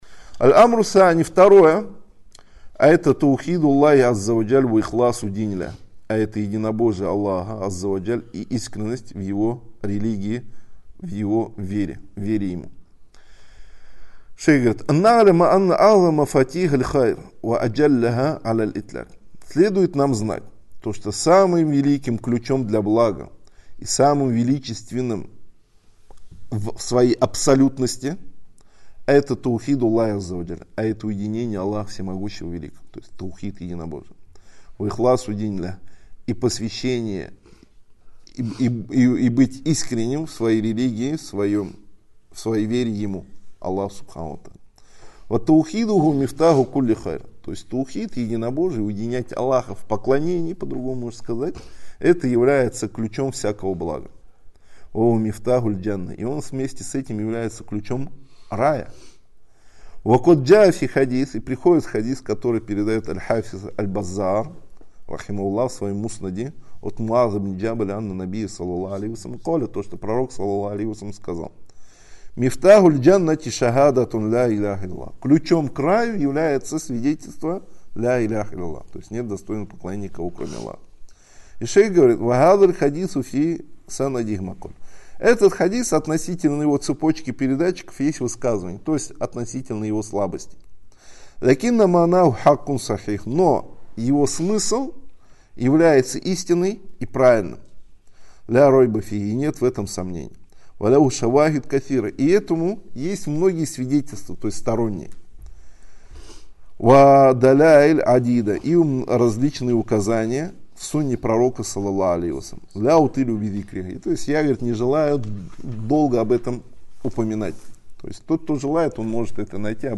Ответы содержатся в этих лекциях по книге шейха ’Абдур-Раззака аль-Бадра.